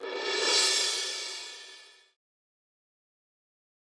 Fx (Orchestra).wav